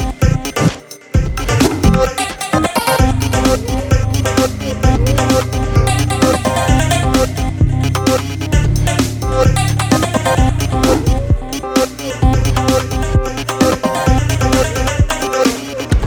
Category: Electro RIngtones